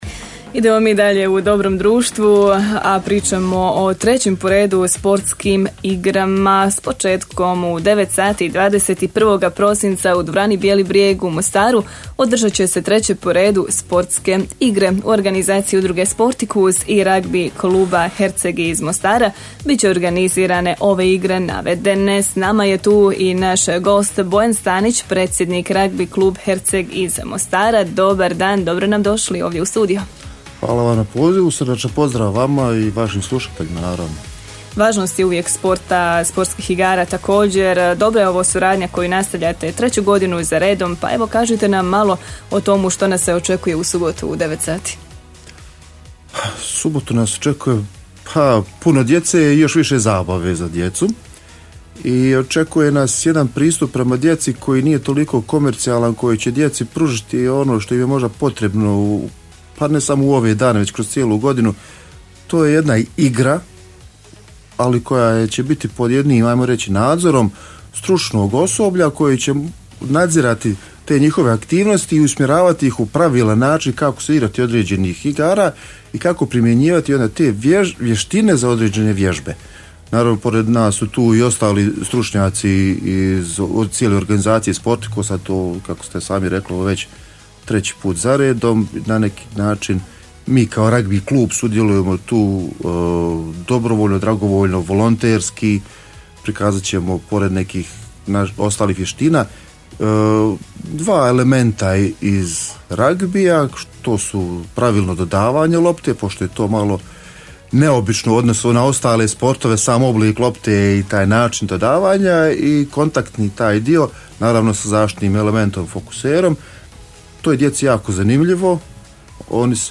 U jutarnjem programu radija Herceg Bosne